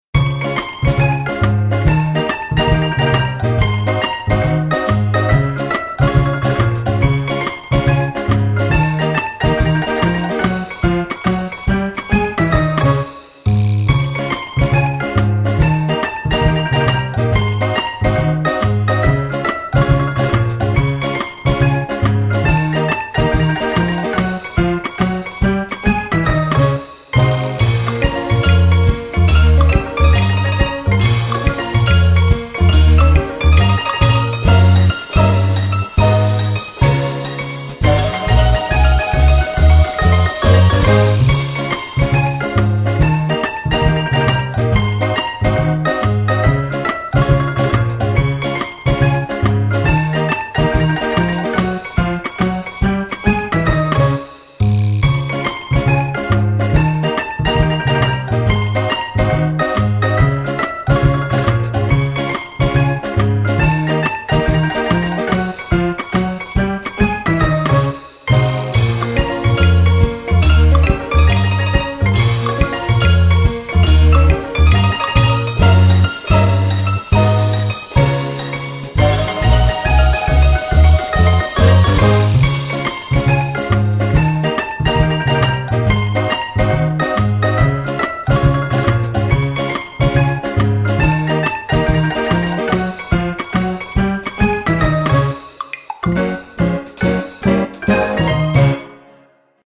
Game music.mp3
Game_music.mp3